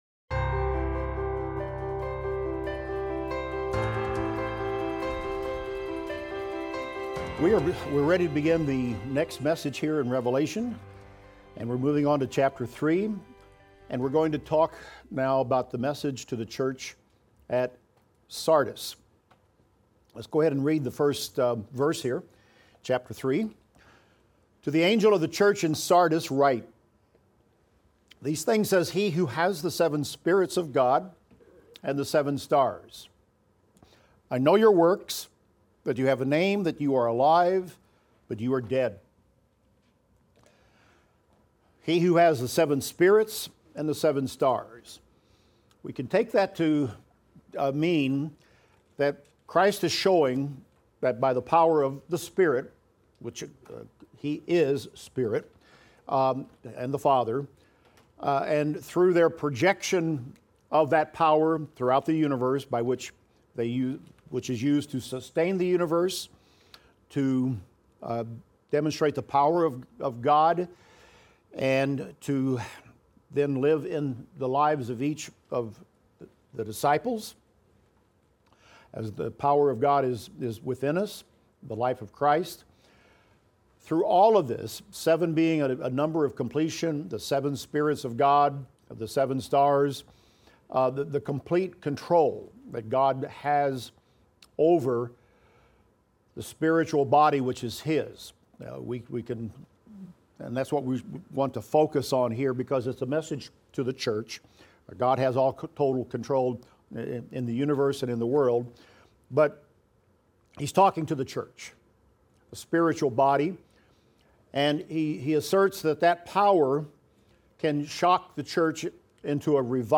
Revelation - Lecture 32 - audio.mp3